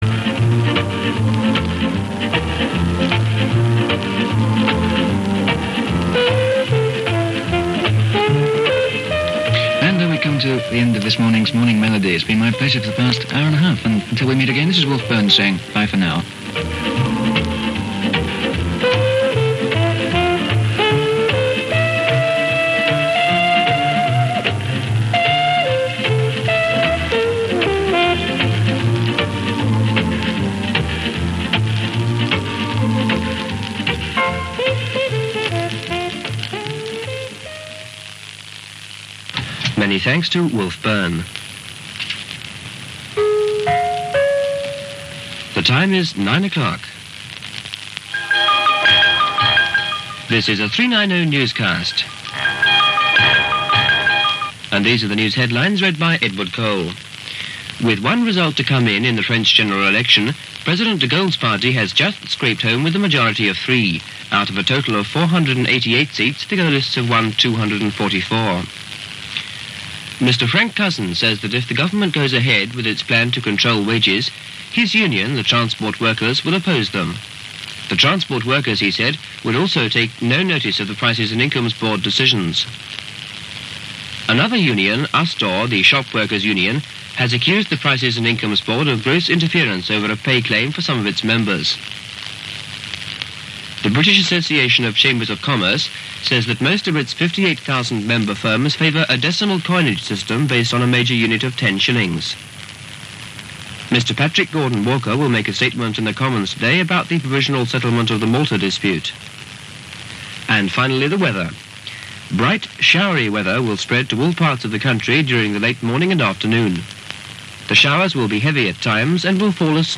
The unique selling point of Radio 390 was its substantially different format - ‘sweet music’ (what     today may be termed ‘smooth’ or ‘easy listening’).